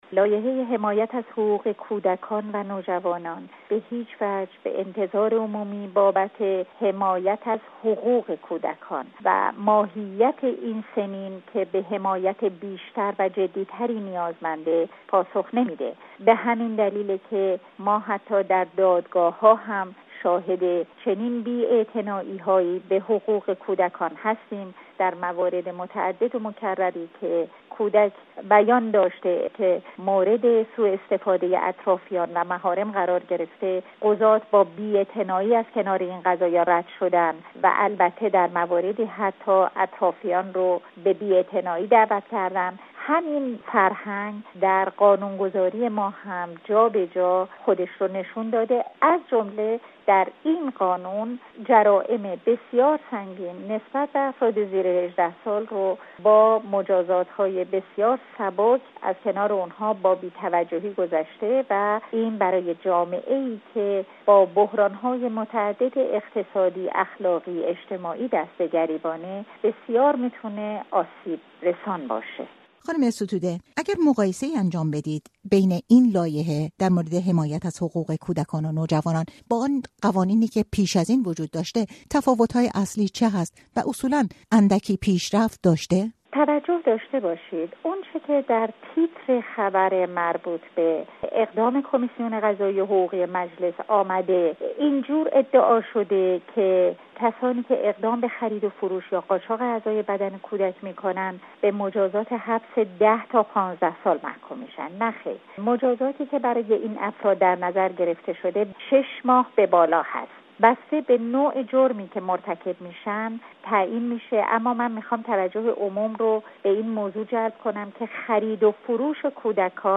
کمیسیون حقوقی و قضائی مجلس برای برای مجرمانی که اقدام به خرید‌ و‌ فروش یا قاچاق اعضای بدن کودک کنند، مجازات حبس درجه ۳ یعنی ۱۰ تا ۱۵ سال تعیین کرده است. گفتگوی رادیو فردا با نسرین ستوده حقوقدان را بشنوید: